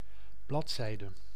Ääntäminen
IPA: /paʒ/